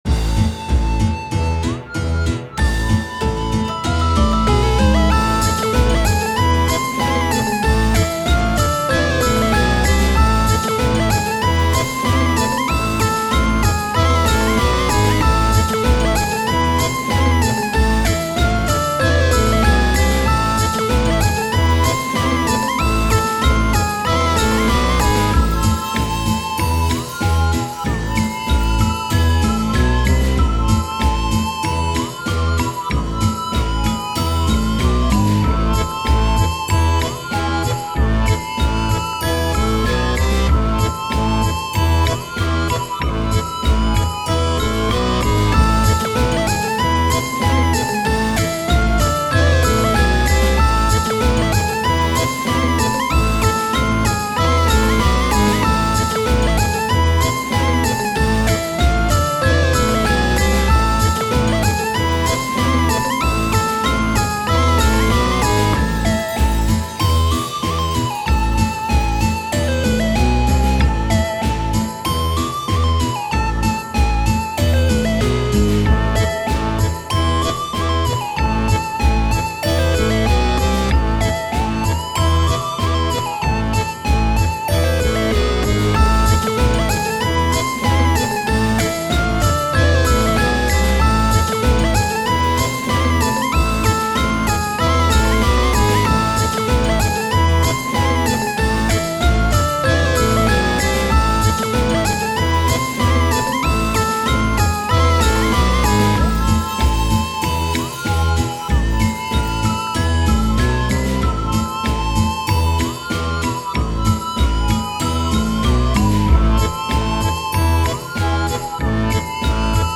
擬態が下手な幽霊もこの日ばかりはハメを外して騒ぐだろうとハイテンションかつ少し間抜けなサウンドに仕上げている。